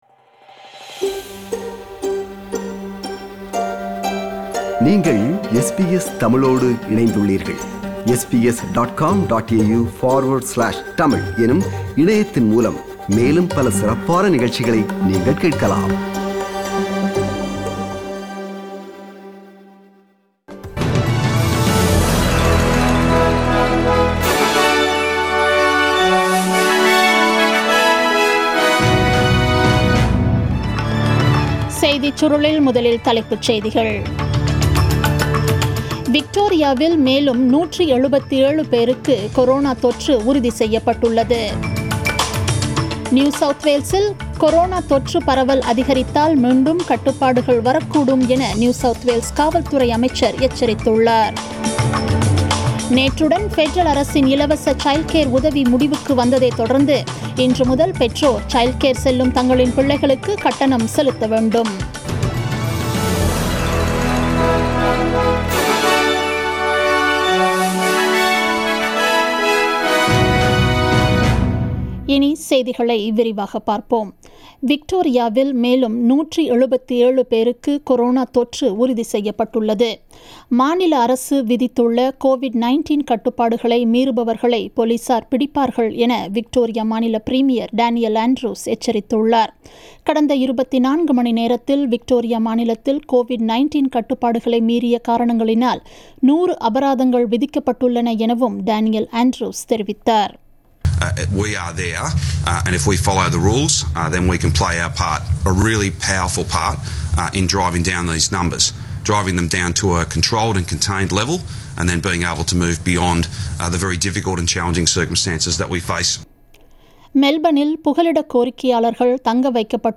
The news bulletin aired on 13th July 2020 at 8pm